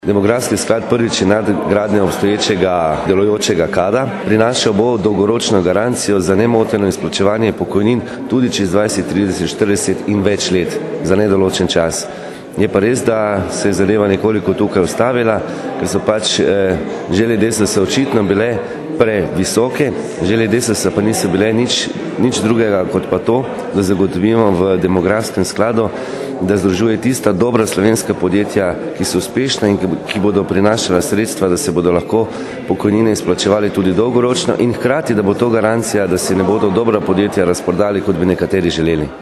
Predstavitve Bele knjige o pokojninah se je v Tržiču udeležil tudi predsednik parlamentarnega Odbora za delo, družino, socialne zadeve in enake možnosti, Uroš Prikl. Predstavil je demografski sklad, ki je nadgradnja   obstoječega, delujočega KAD-a.
izjava_urosprikl.mp3 (947kB)
98431_izjava_urosprikl.mp3